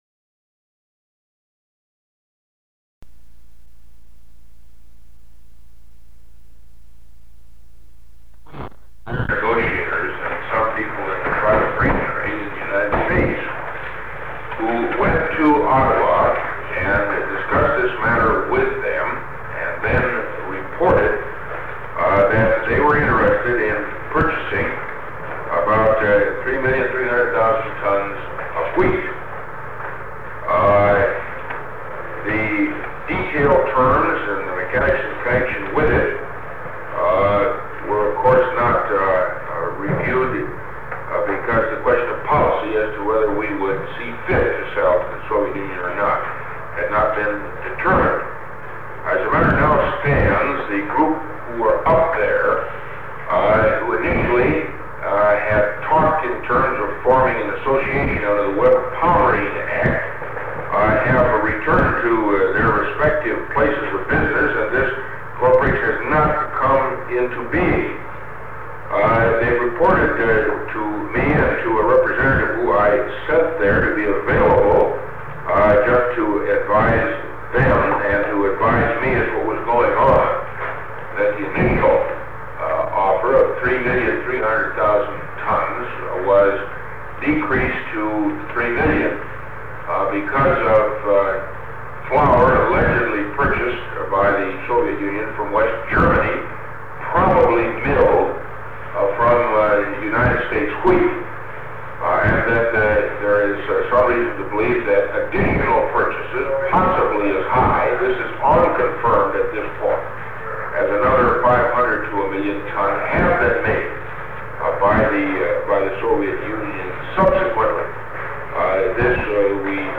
Sound recording of a National Security Council (NSC) meeting held on September 30, 1963, concerning wheat sales to the Soviet Union. Discussed are Canadian-Soviet wheat negotiations and the possible sale of U.S. wheat to the Soviet Union and political pitfalls of such an action. The first eight minutes of the meeting are recorded and then the recording skips to the end of the meeting when President John F. Kennedy asks a smaller group of several staff members to stay to continue the discussion.